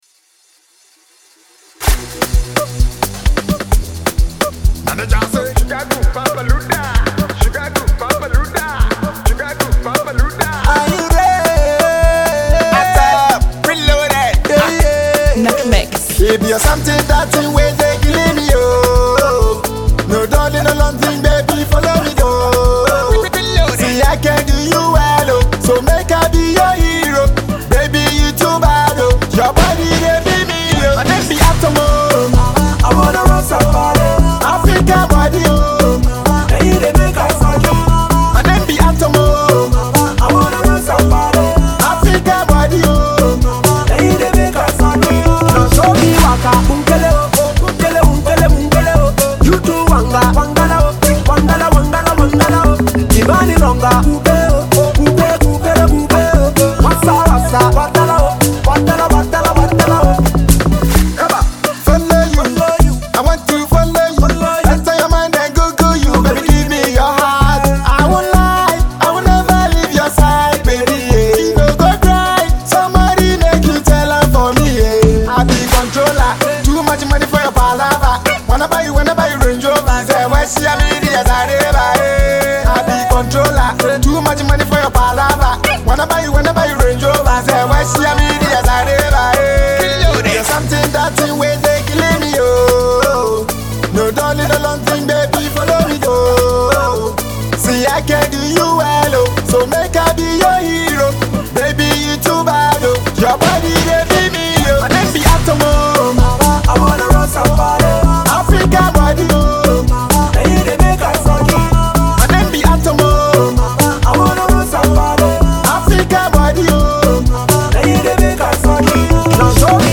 smooth vocals and catchy lyrics